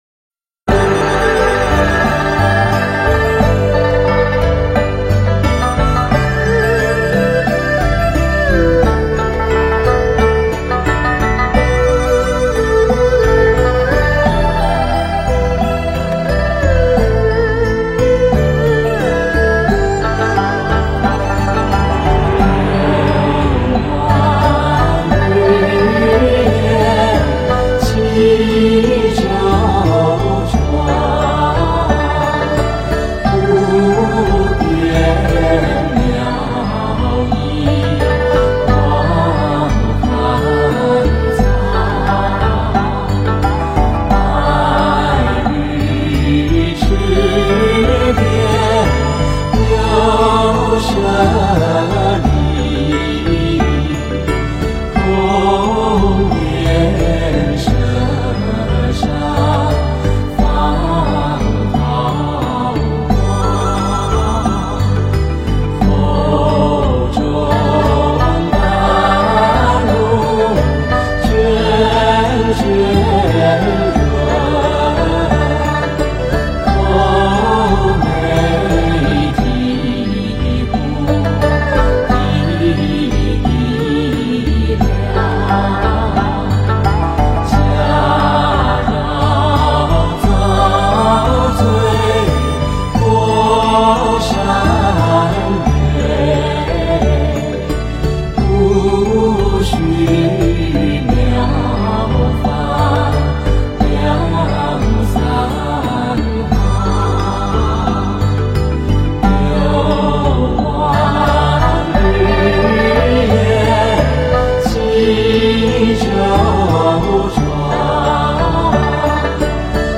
法华偈 诵经 法华偈--圆满自在组 点我： 标签: 佛音 诵经 佛教音乐 返回列表 上一篇： 浴佛偈颂 下一篇： 六字大明咒 相关文章 求佛--誓言 求佛--誓言...